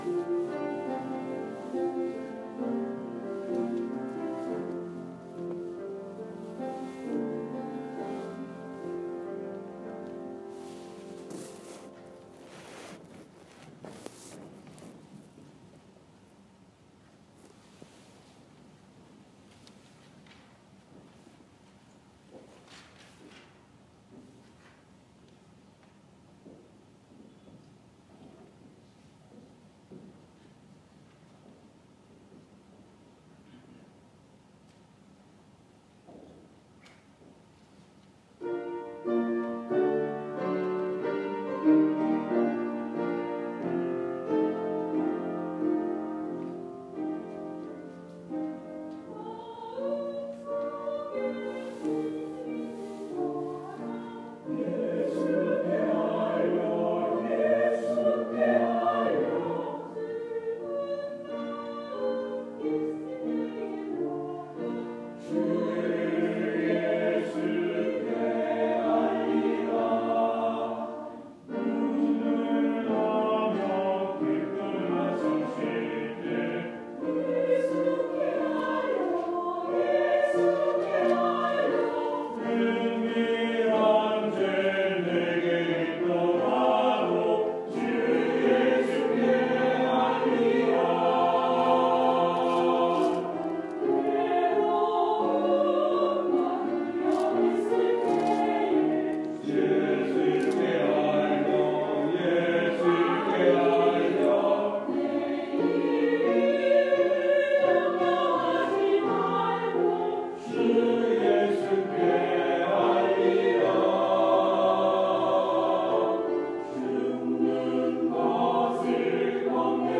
2월 14일 주일 찬양대(주예수께 알리라, 로렌쯔곡)